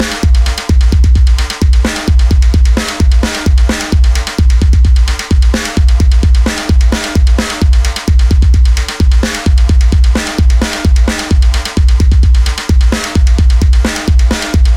描述：舞蹈、嘻哈、电子摇滚节拍
Tag: 130 bpm Electronic Loops Drum Loops 2.48 MB wav Key : Unknown